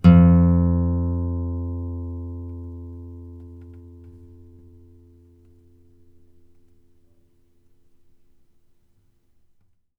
bass-12.wav